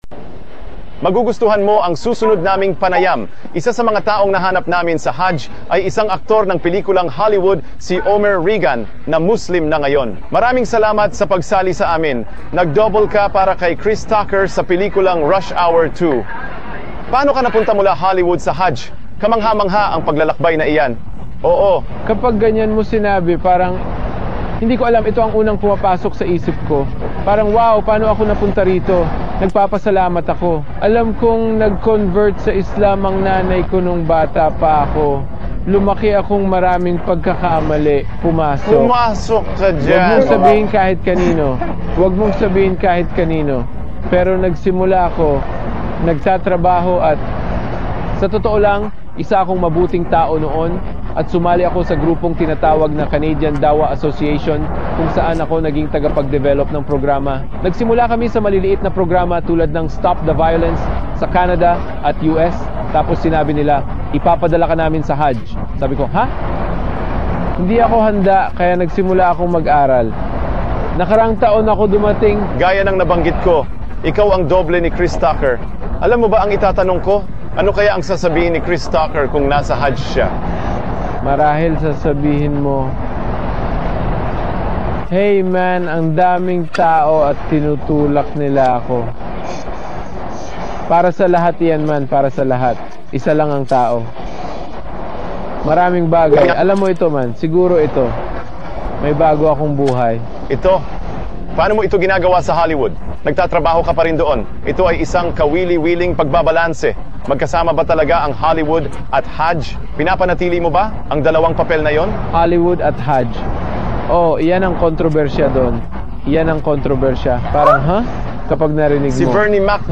Paglalarawanˇ: Si Omar Regan, isang Hollywood actor, ay iniinterbyu ng internasyonal na TV channel na Al-Jazeera habang isinasagawa niya ang ika-5 Haligi ng Islam.